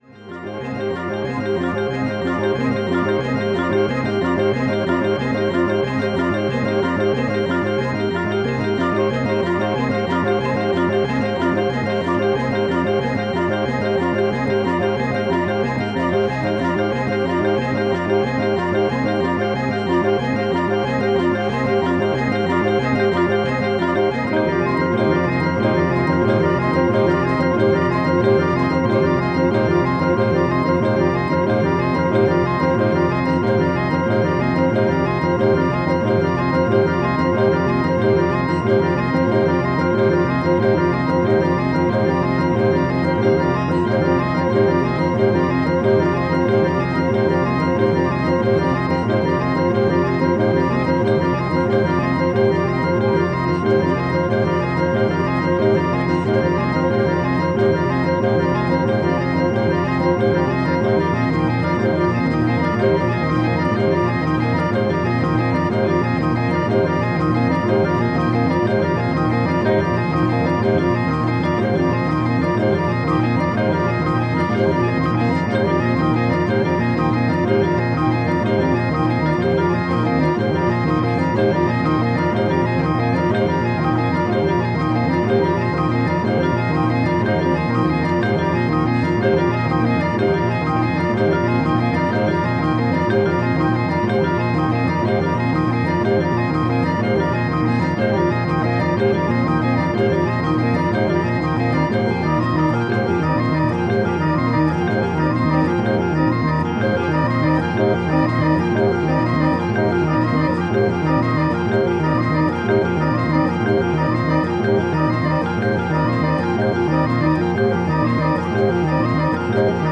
minimalist composition